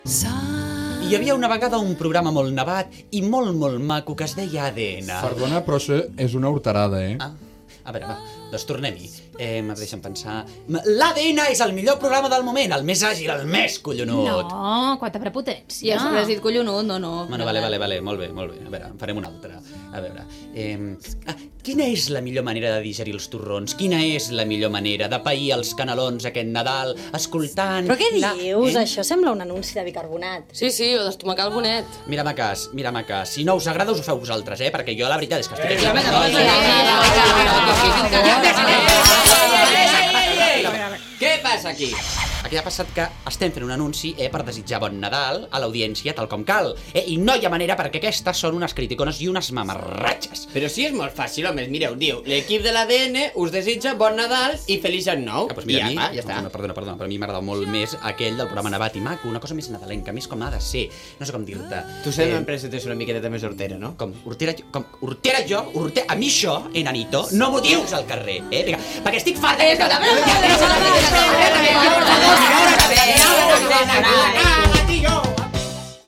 RAC 1 Emissora RAC 1 Barcelona
Promoció nadalenca del programa